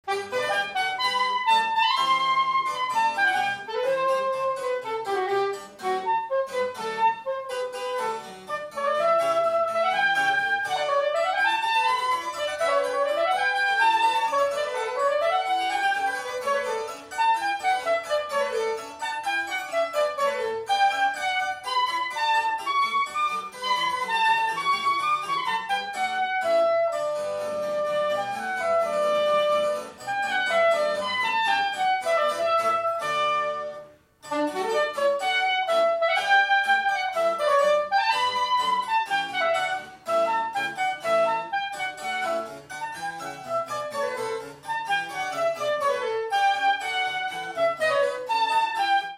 A few examples of my own playing are offered for your perusal.
Soprano Saxophone:
Sonata in G Minor = Giovanni Platti, a four movement Baroque piece originally written for flute. This is a transcription for soprano saxophone and piano. We have converted the arrangement into a harpsichord accompaniment. The example is from the IV movement.